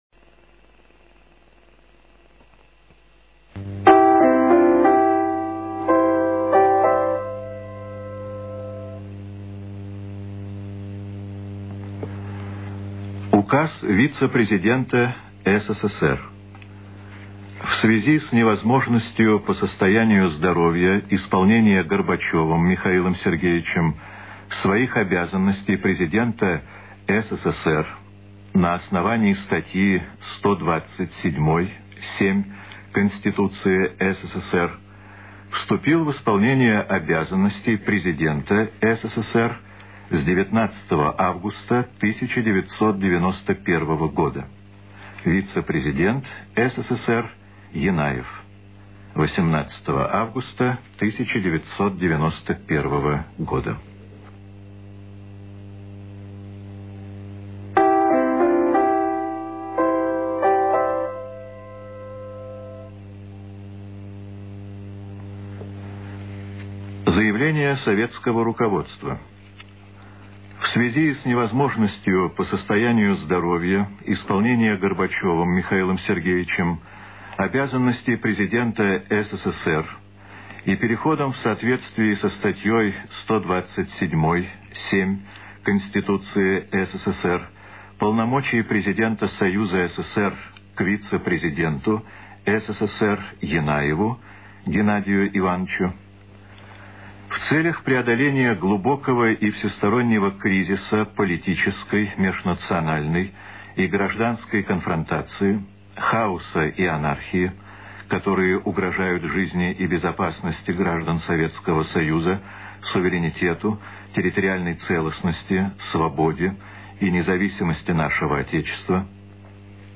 20 лет назад, начиная с 6 утра, с 18-го на 19-е августа жители СССР услышали сначала по радио, а затем и в телеэфире, следующее обращение правительства